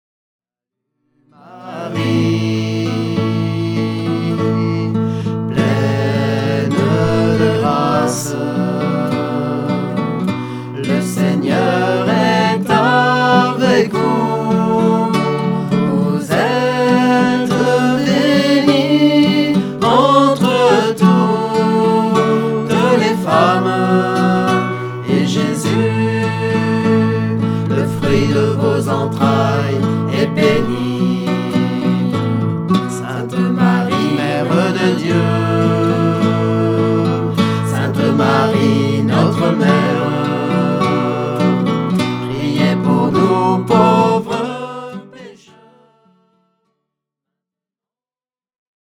Chaque mystère est accompagné de chants pour la louange
Format :MP3 256Kbps Stéréo